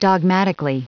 Prononciation du mot dogmatically en anglais (fichier audio)
Prononciation du mot : dogmatically
dogmatically.wav